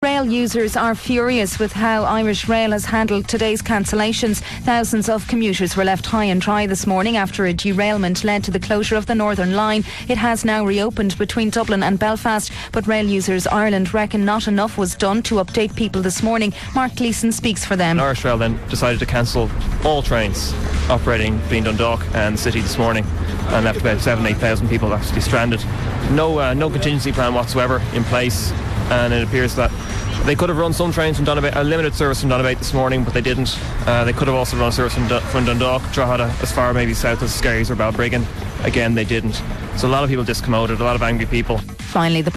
FM104 - News, January 11th 2008